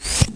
1 channel
rotate01.mp3